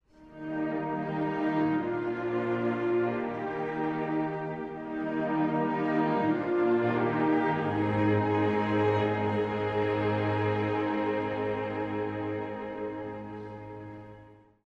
古い音源なので聴きづらいかもしれません！（以下同様）
補足｜実際に大砲を使った録音
現在出回っている音源ので有名なのは、ドラティ＆ミネアポリス交響楽団という組み合わせのものです。
大砲の迫力あるサウンドを聴いてみたい方はお試しください！
まさに「ドゴォン！！！」のとおりの効果音です笑